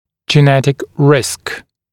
[ʤɪ’netɪk rɪsk][джи’нэтик риск]генетический риск